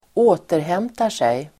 Uttal: [²'å:terhem:tar_sej]